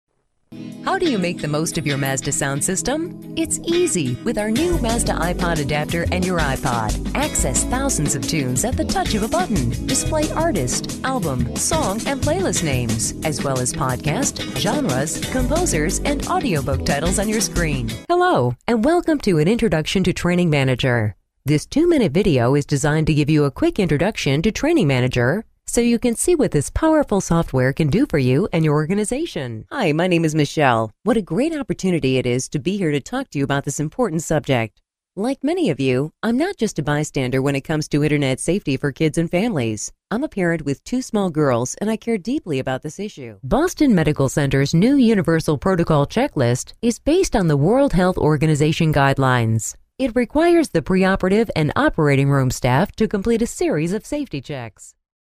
Sprechprobe: eLearning (Muttersprache):